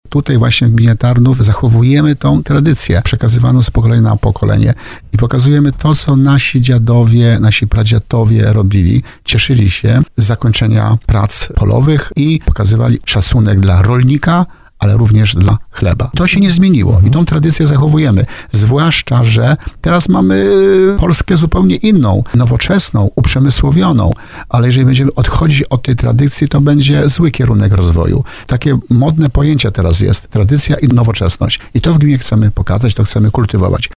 Wójt Grzegorz Kozioł podkreśla, że w tym regionie dożynki to nie tylko konkurs na najpiękniejszy wieniec, ale żywa tradycja, przekazywana z pokolenia na pokolenie.